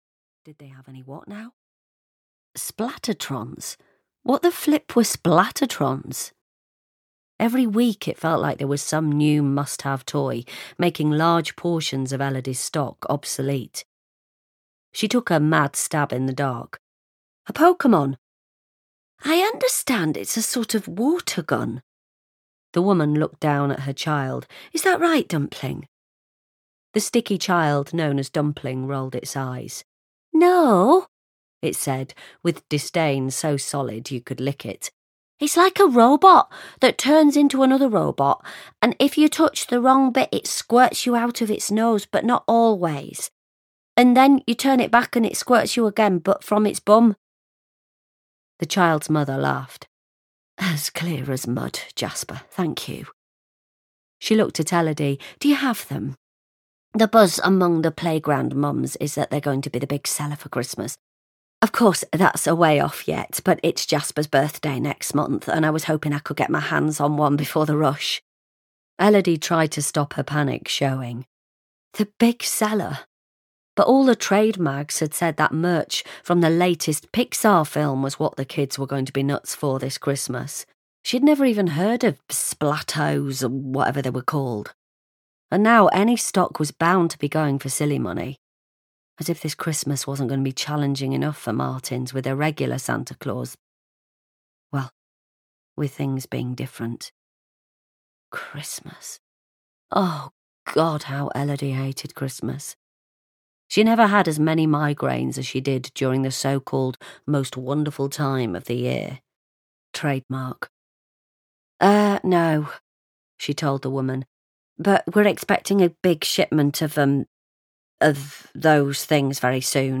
Santa Maybe (EN) audiokniha
Ukázka z knihy